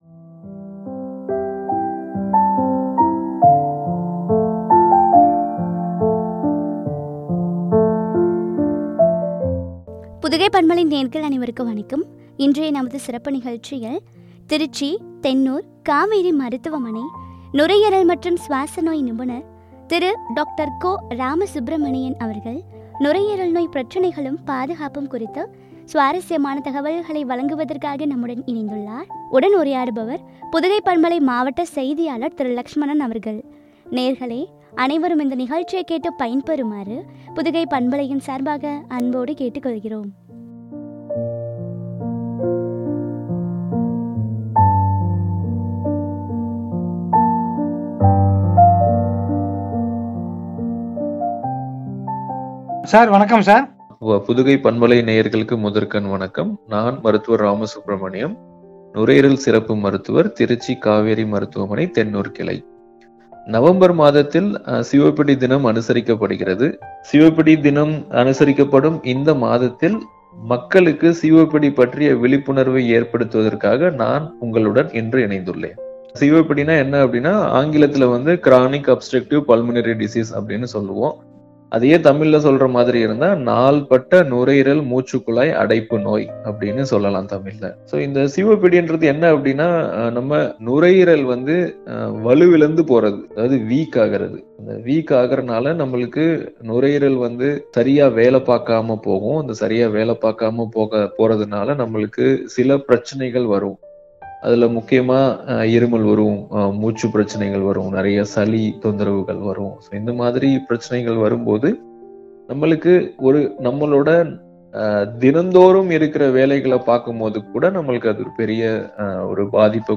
பாதுகாப்பும் குறித்து வழங்க உரையாடல்.